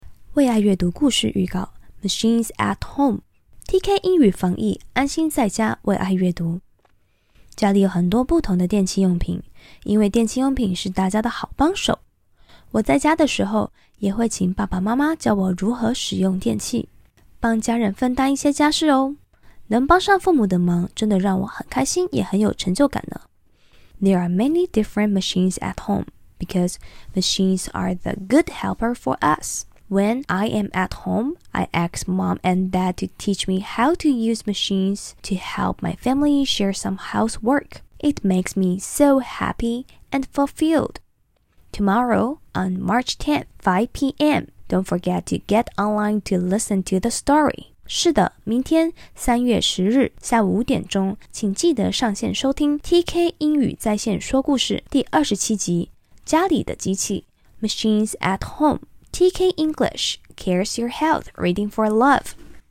导读音频：